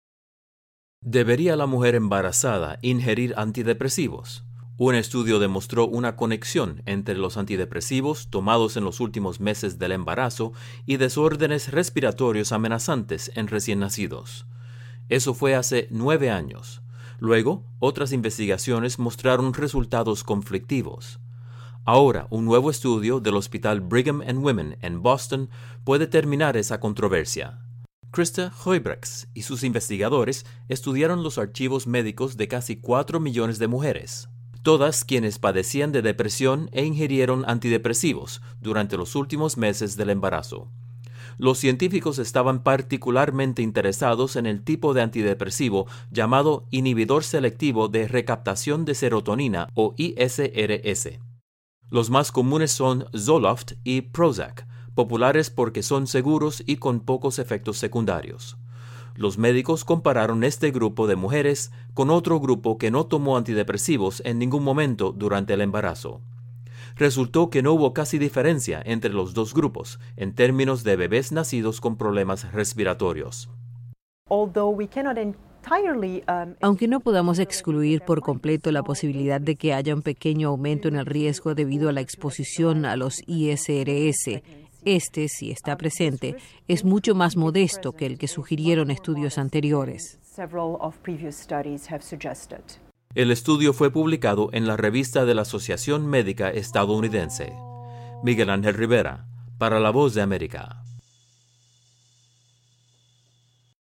VOA Informe salud: Antidepresivos y recién nacidos